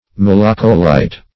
Search Result for " malacolite" : The Collaborative International Dictionary of English v.0.48: Malacolite \Mal"a*co*lite\, n. [Gr. malako`s soft + -lite.]